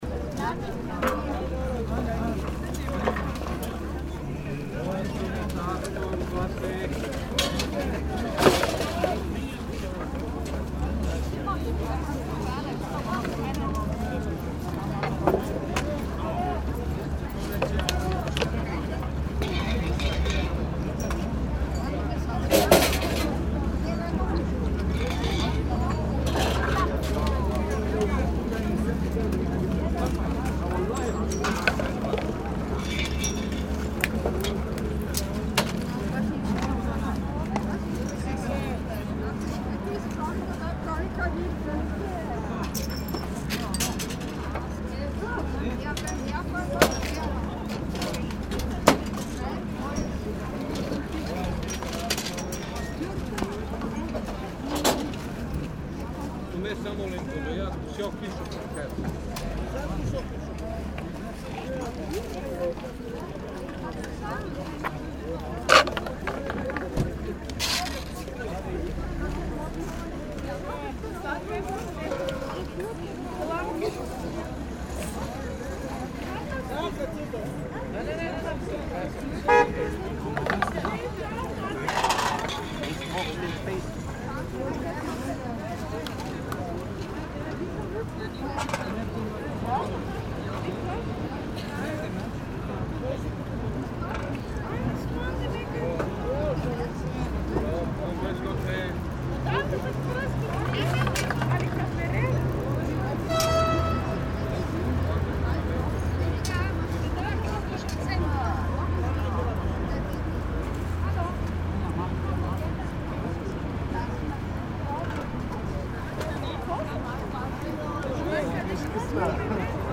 the eight recordings that constitute "soundmap vienna" originate partly from locations inside the city that bear an everyday relationship with me and partly have been "discovered" during a few occasional strolls. what they have in common is that they don’t bear an apparent reference to vienna, neither acoustically nor visually – no fiakers and no vienna boys choir, sorry for that – and that most of them were taken at unusual times during the day. these recordings were left unprocessed and subsequently arranged into a 19-minute long collage. the second, more experimental collage was composed out of midi-data and soundfiles that have been extracted via specific software from the photographs taken at the recording sites.
Flohmarkt_am_Naschmarkt.mp3